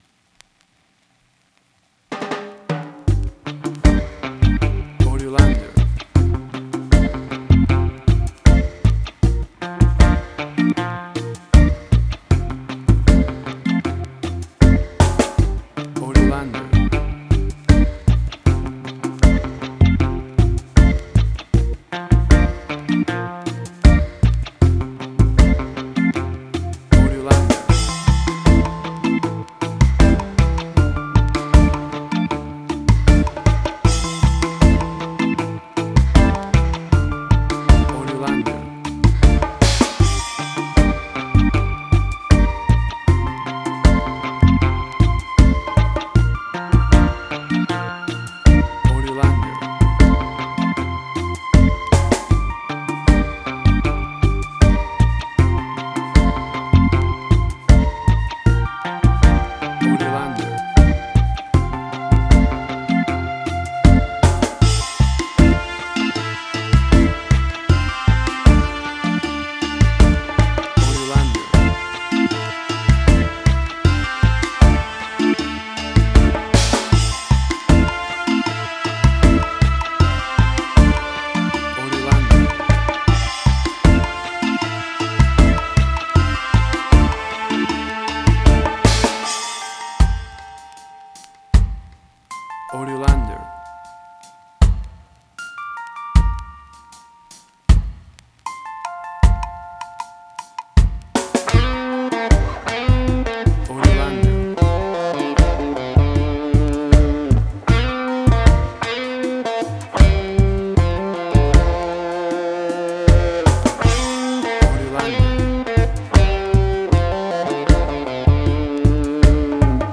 Reggae caribbean Dub Roots
Tempo (BPM): 78